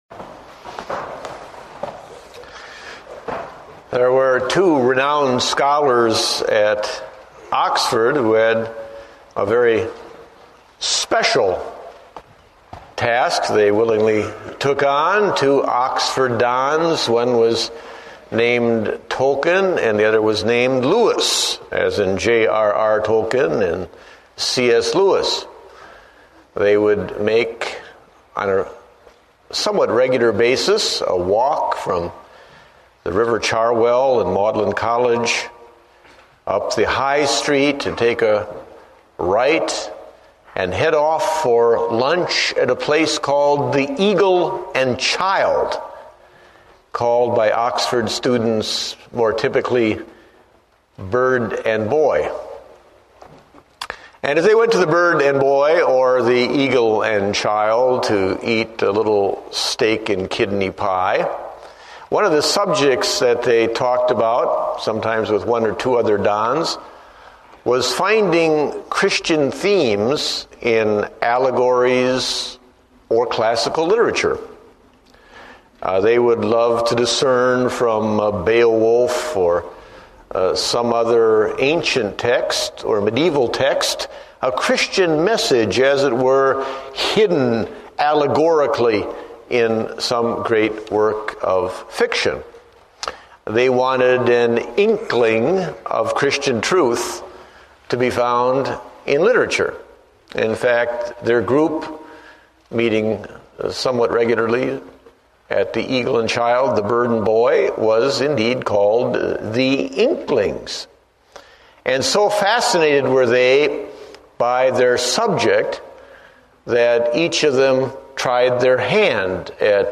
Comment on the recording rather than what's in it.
Date: December 21, 2008 (Morning Service)